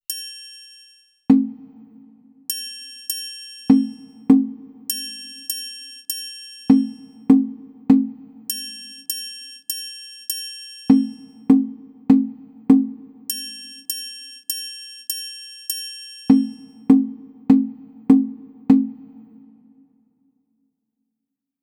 Motifs musicaux